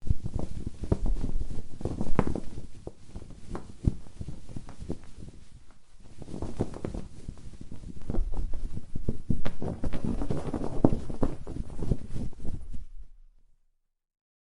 Wind On Sail
Wind On Sail is a free nature sound effect available for download in MP3 format.
Wind on Sail.mp3